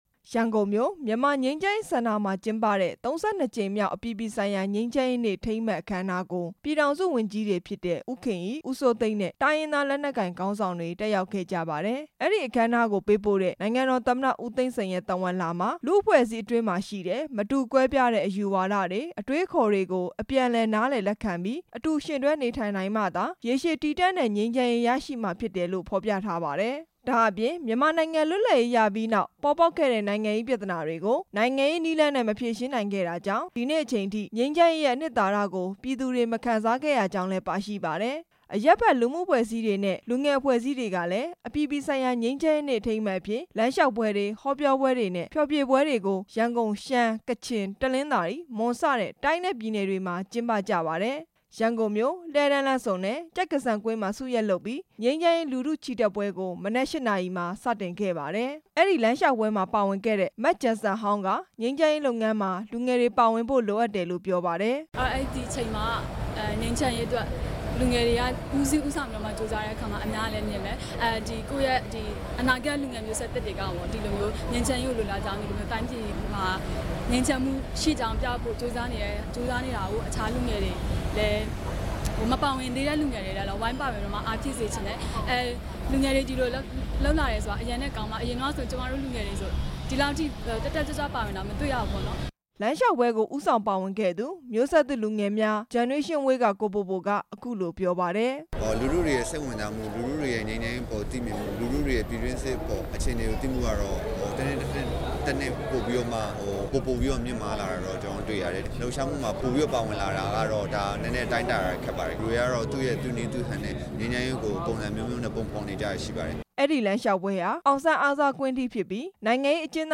ငြိမ်းချမ်းရေးနေ့ အခမ်းအနားတွေအကြောင်း တင်ပြချက်